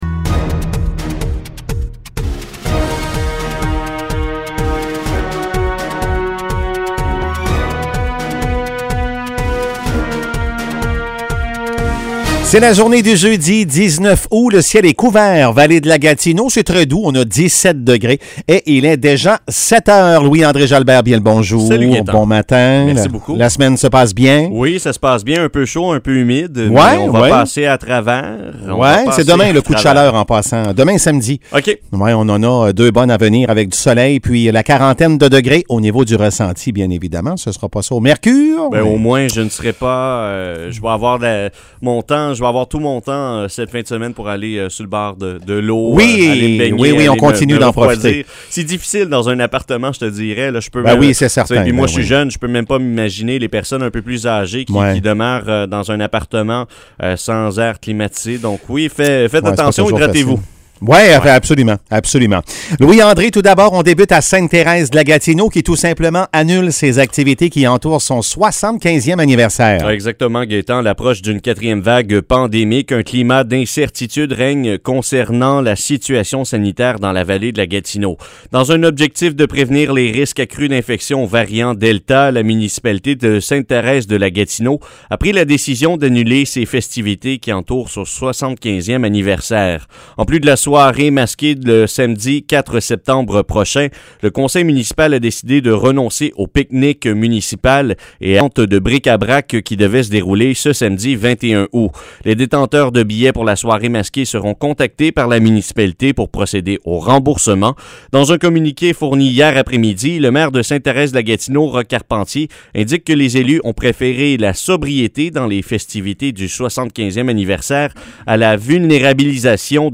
Nouvelles locales - 19 août 2021 - 7 h